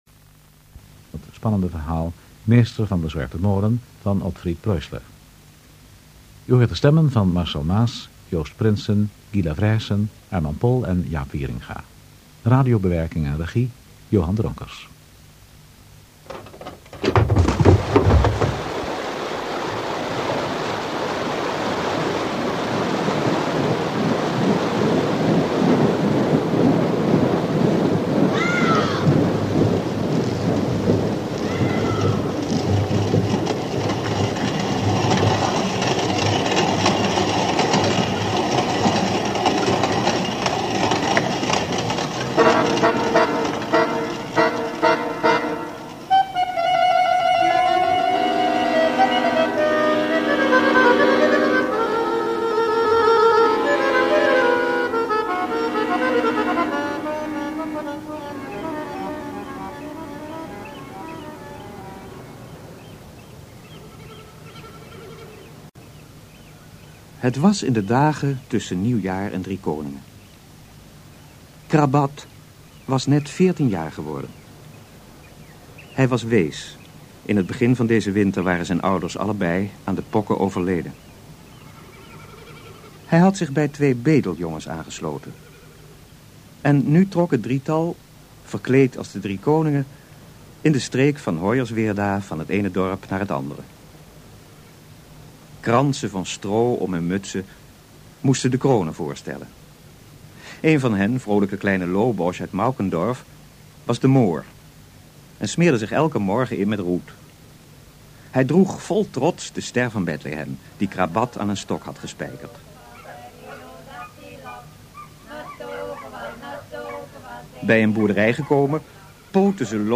Dit 3-delige hoorspel duurt in totaal ongeveer 2 uur en 48 minuten.